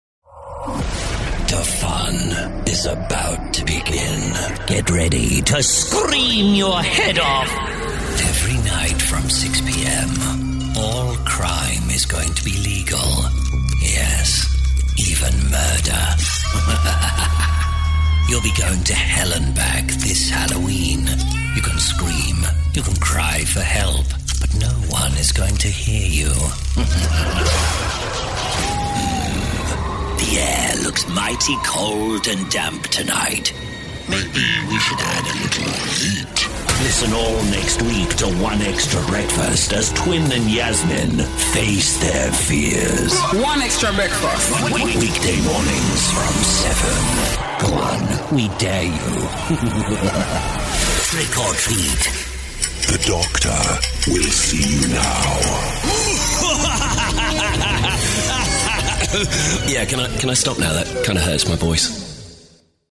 Spooky and fun Halloween voice styles including Dracula, Vincent Price and Joker tones.
Whether you need eerie narration, a sinister villain, or full Vincent Price-style drama, I’ve voiced it.
I specialise in chilling, atmospheric reads, from PURE EVIL to comedic spooky characters like Spooky Halloween Laughs. My Halloween range covers promos, radio ads, trailers, and theme park events across the UK.